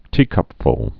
(tēkŭp-fl)